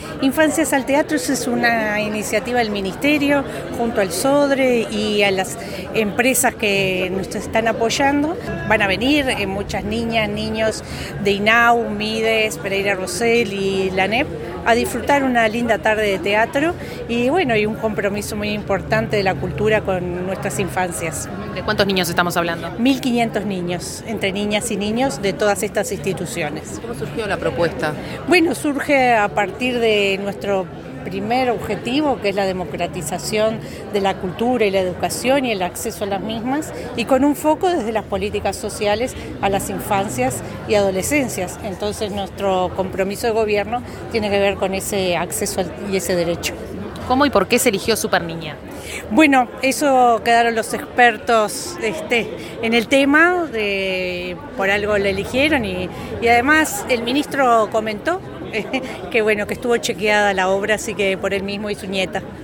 Declaraciones de la ministra interina de Educación y Cultura, Gabriela Verde